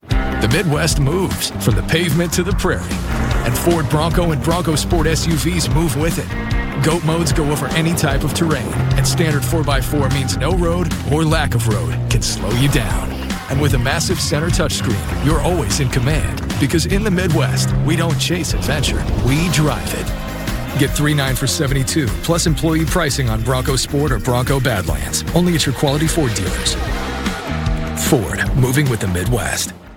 Commercials Download This Spot